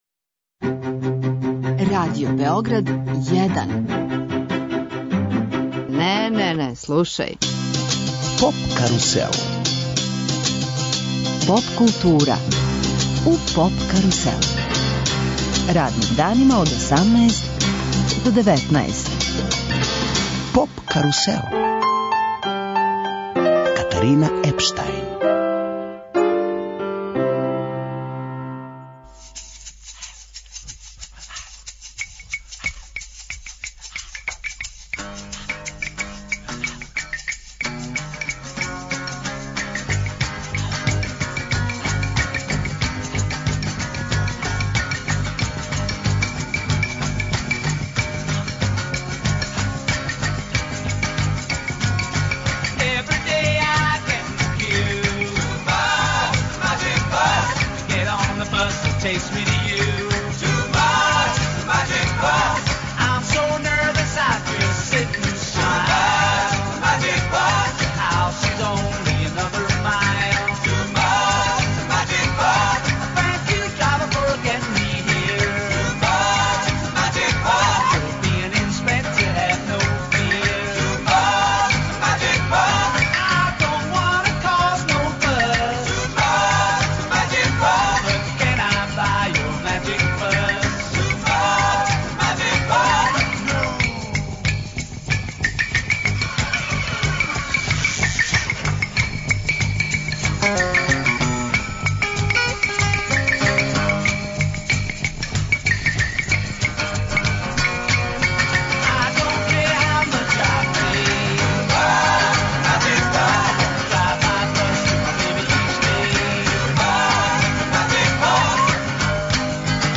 Гост емисије је познати хип хоп аутор Ђорђе Миљеновић. Изашавши из оквира трија Bad Copy, формирао је свој бенд и свој музички таленат показао у другим жанровима.